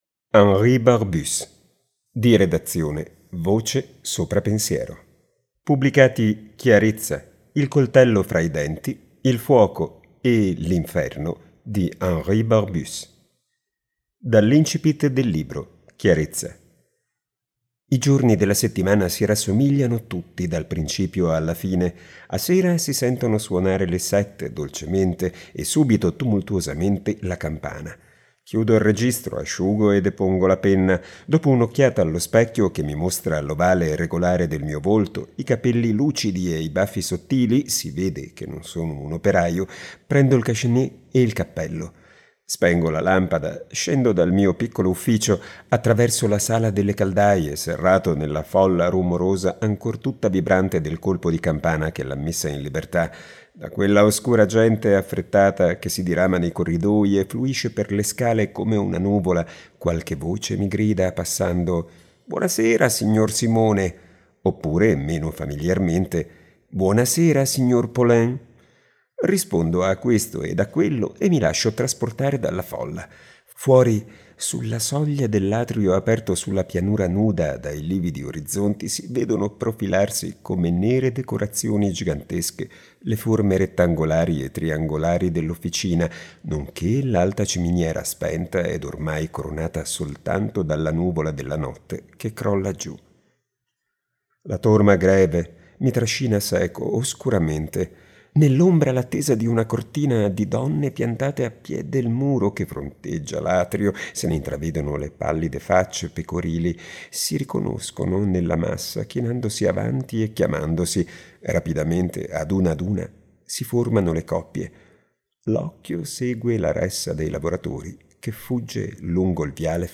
Dall’incipit del libro (Chiarezza):